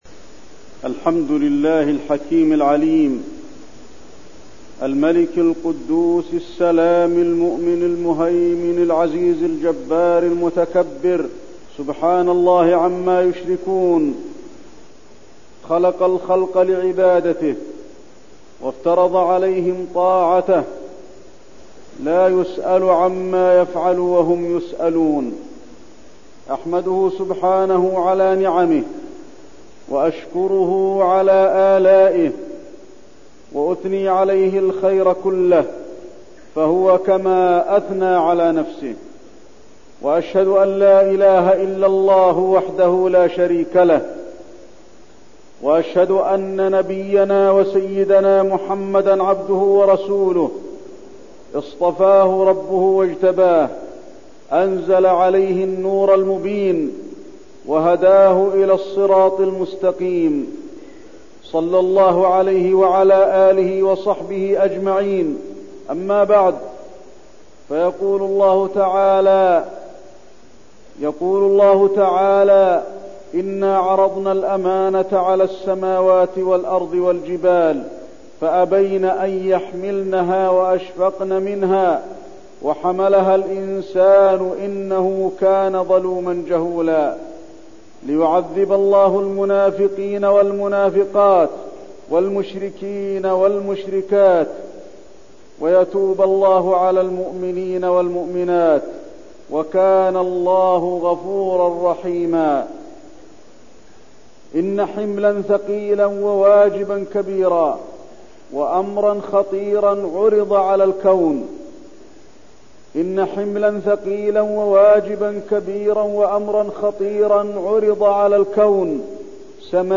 تاريخ النشر ١٥ ذو القعدة ١٤١٠ هـ المكان: المسجد النبوي الشيخ: فضيلة الشيخ د. علي بن عبدالرحمن الحذيفي فضيلة الشيخ د. علي بن عبدالرحمن الحذيفي الأمانة